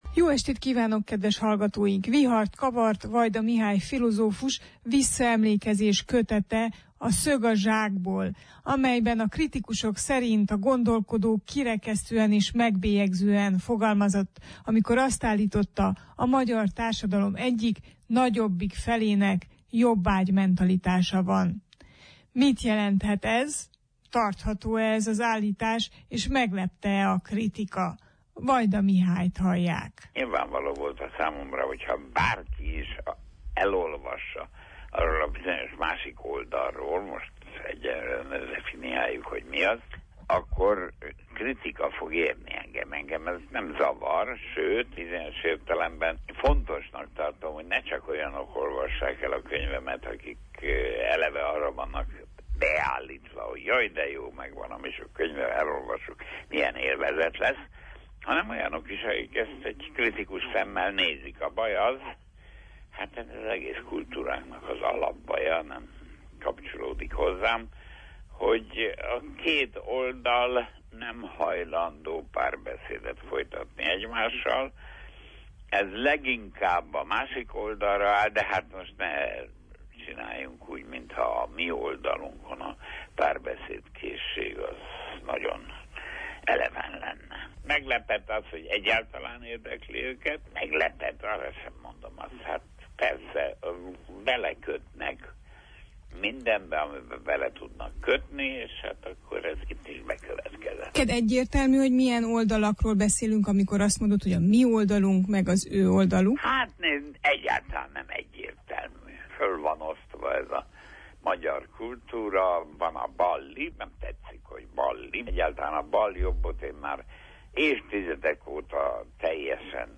Ránk sem jellemző, hogy meg akarnánk érteni a másik oldalt. Vajda Mihály interjú